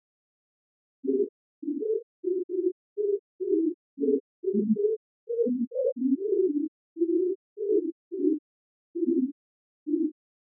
5.5— How does polyphony come out?